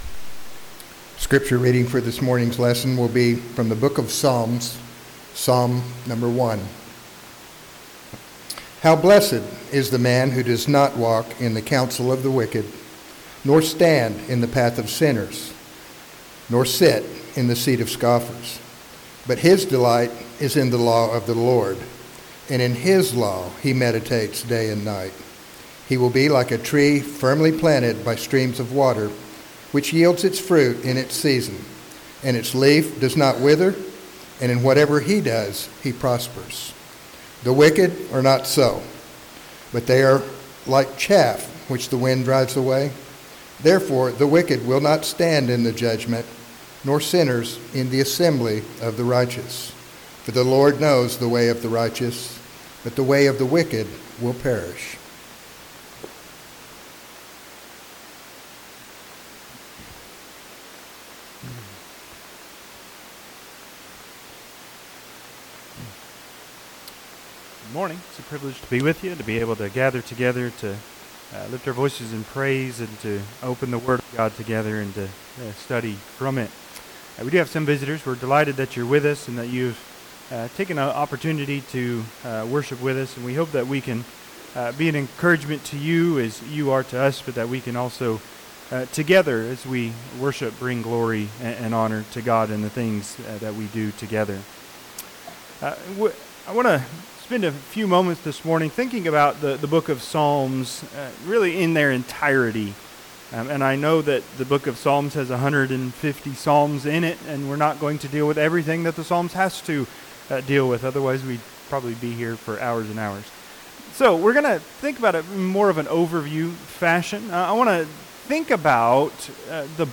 Psalm 1 Service Type: Sunday AM An overview of considering how the Psalms apply for the Christian's daily life.